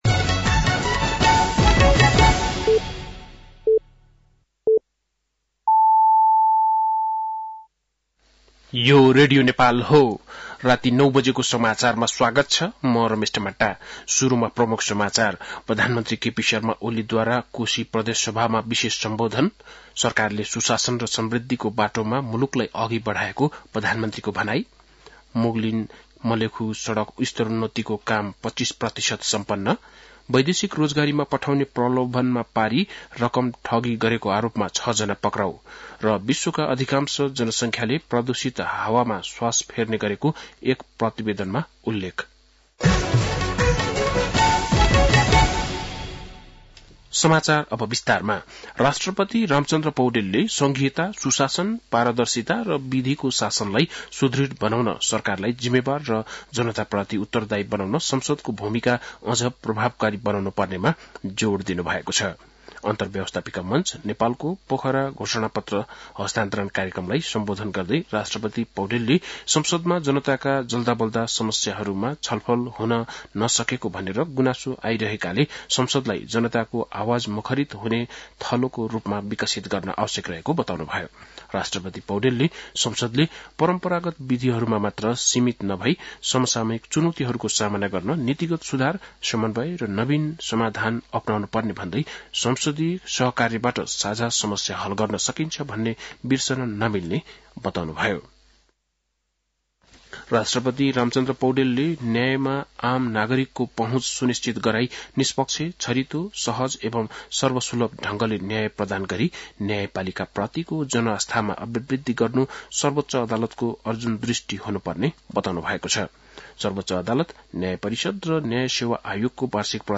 बेलुकी ९ बजेको नेपाली समाचार : २८ फागुन , २०८१